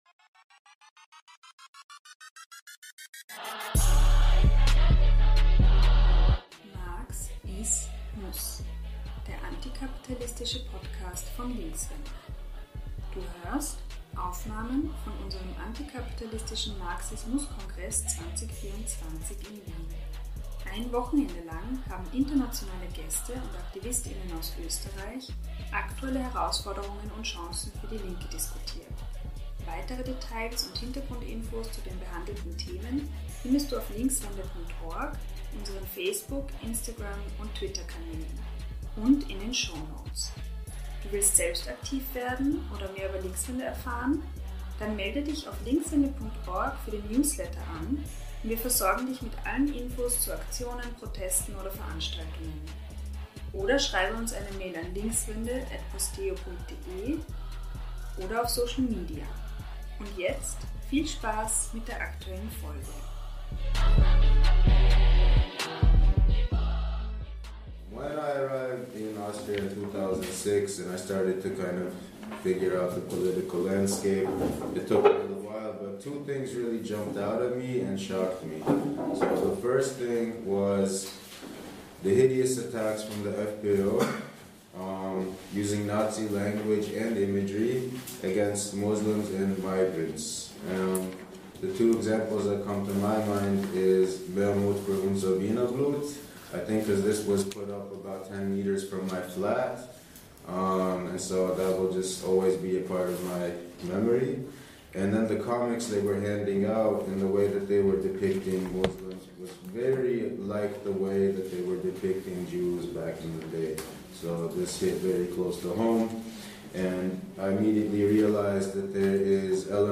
Du hörst den Vortrag „Ist Kickl Hitler 2.0?